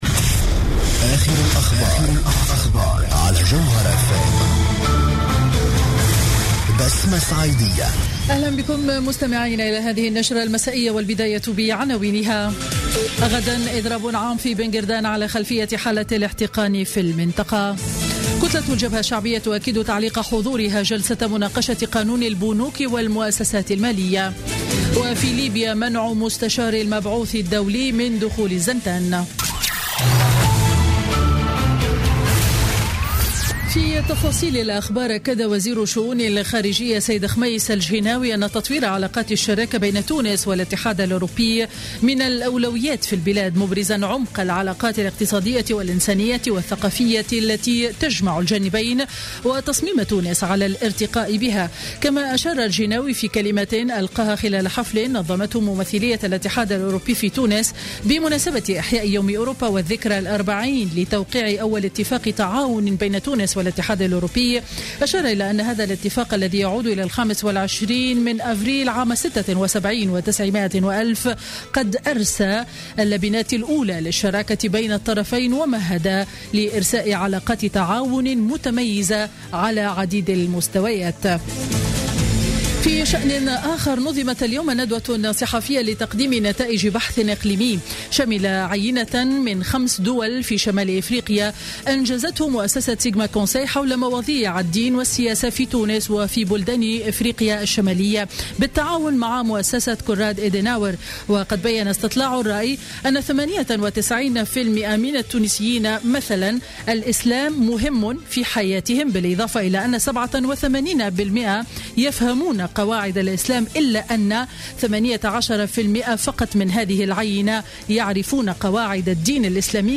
نشرة أخبار السابعة مساء ليوم الثلاثاء 11 ماي 2016